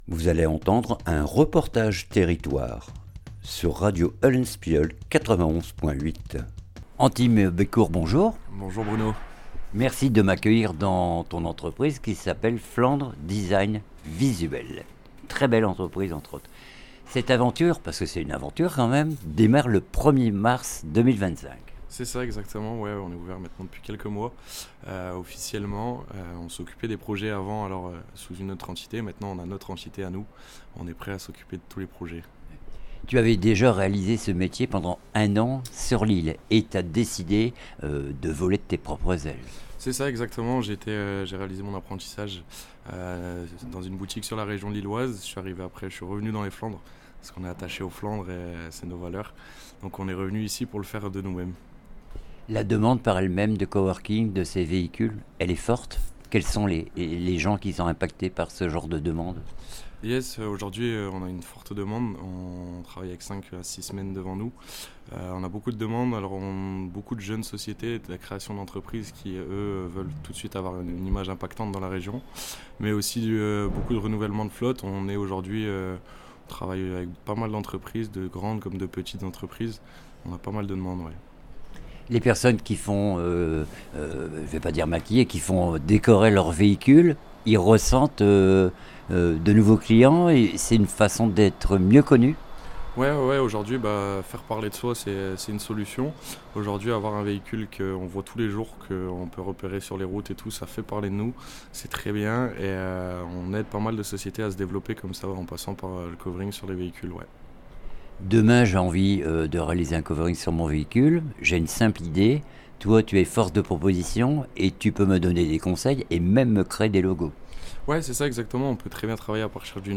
REPORTAGE TERRITOIRE FLANDRES DESIGN VISUEL